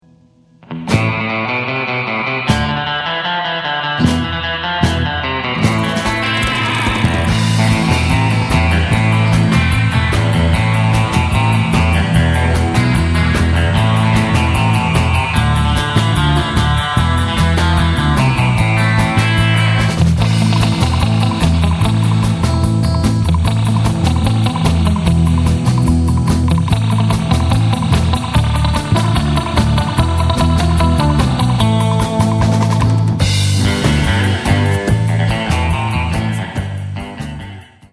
surf, hotrod, space, and spaghetti western tunes